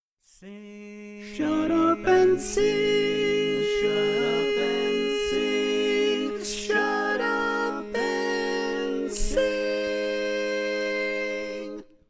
Key written in: A♭ Major
How many parts: 4
Type: Barbershop